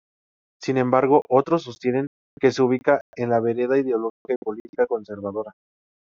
Pronounced as (IPA) /beˈɾeda/